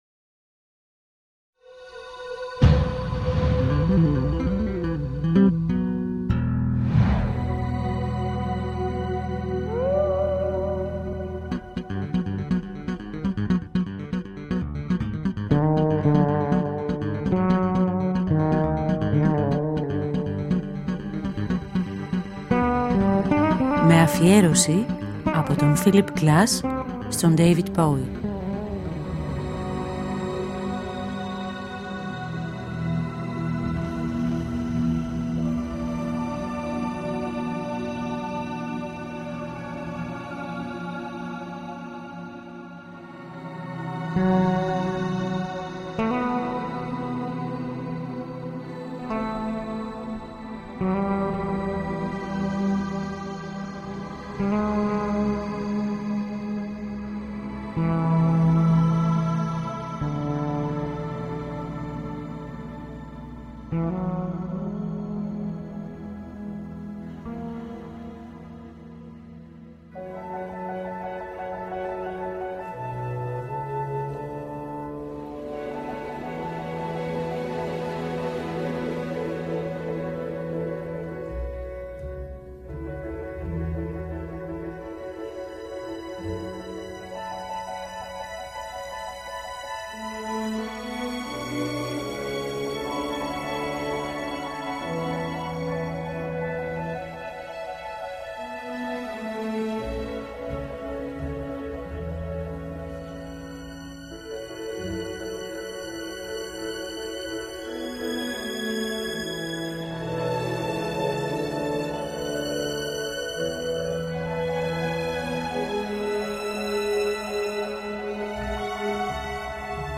Ακούμε αποσπάσματα από τη Συμφωνία No 1 “Low” και από τη Συμφωνία No 4 “Heroes” που έγραψε ο Philip Glass εμπνεόμενος από τα άλμπουμ “Low” και “Heroes” των David Bowie και Brian Eno. Σκέψεις και λόγια του Philip Glass για τη συνεργασία αυτή και για τη διεύρυνση των ορίων στη μουσική σύνθεση.